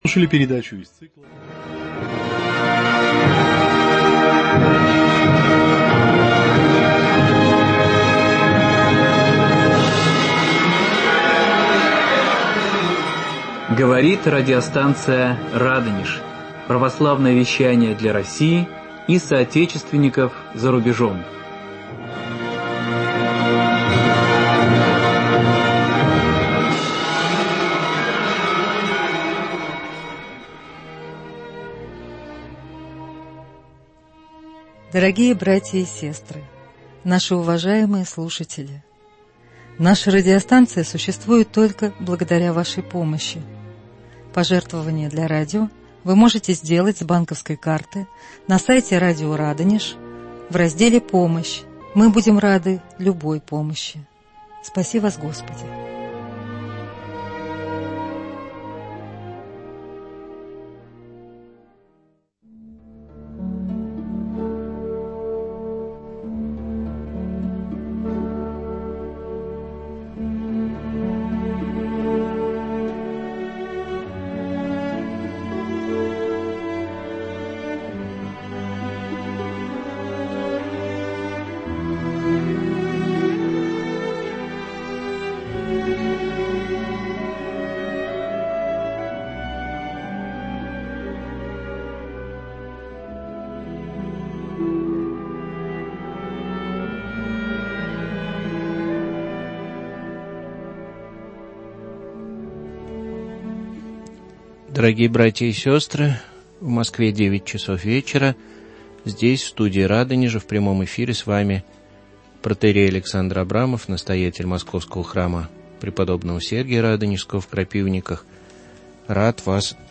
Прямой эфир.
отвечает на вопросы радиослушателей.